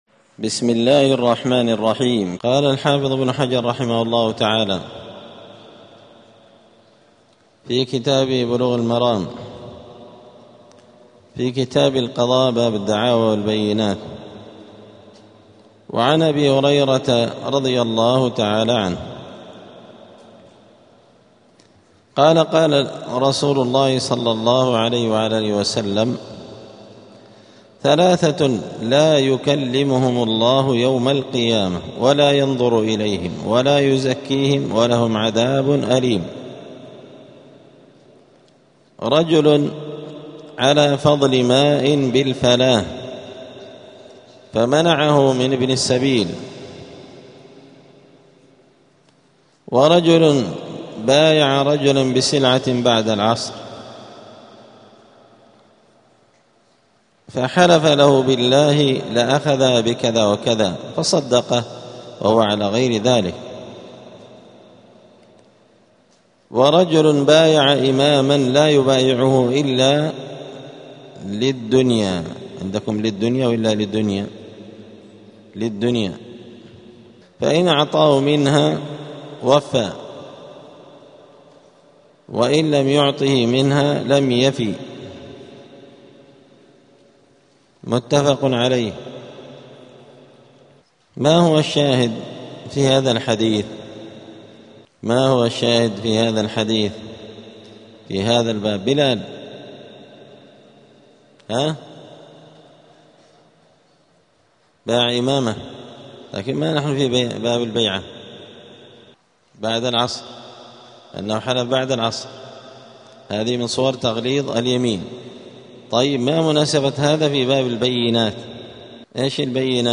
*الدرس الخامس والعشرون (25) {ﺗﻐﻠﻴﻆ اﻟﺤﻠﻒ ﺑﺎﻟﻤﻜﺎﻥ ﻭاﻟﺰﻣﺎﻥ ﻫﻞ ﻳﺠﻮﺯ ﻟﻠﺤﺎﻛﻢ ﺃﻭ ﻻ}*